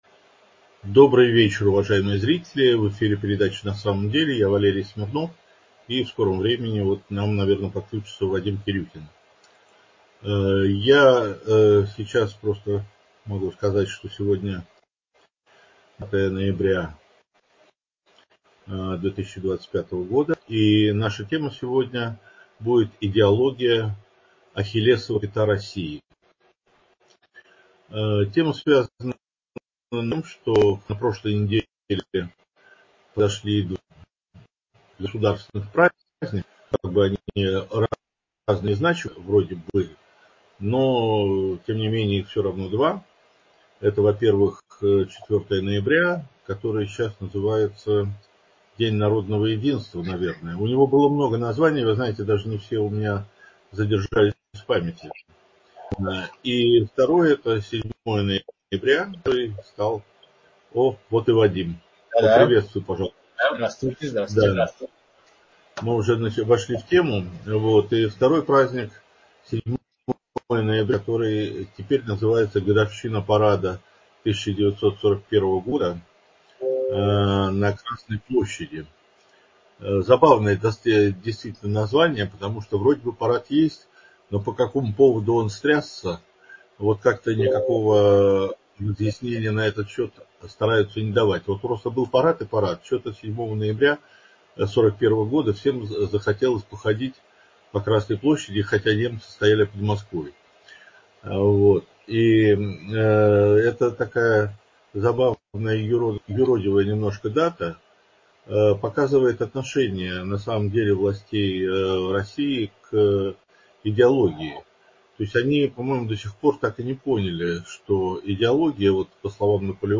Информационно-аналитическая передача. Темы обзоров - подоплека важнейших политических и экономических событий в России и за рубежом.